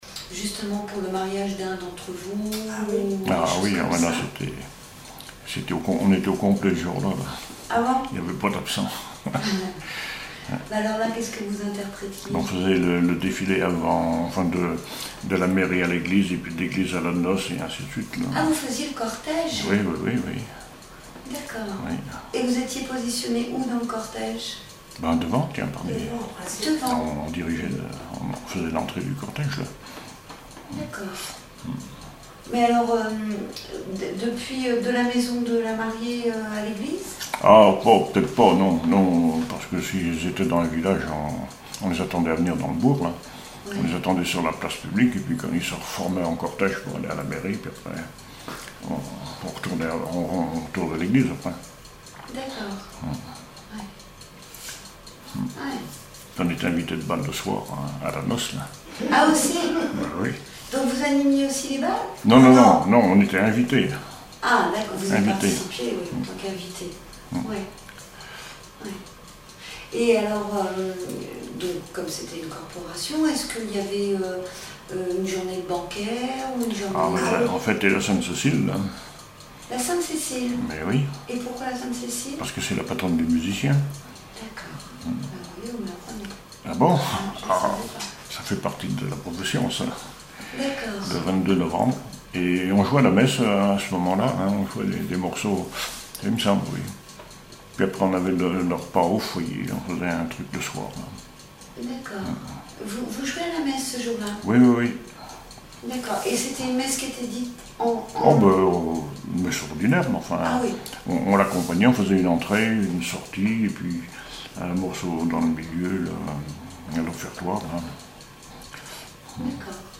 témoignages sur la musique et une chanson
Catégorie Témoignage